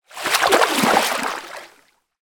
Звук плеска воды хищника